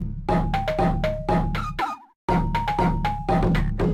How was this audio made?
Recorded the song in-game